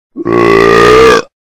Burp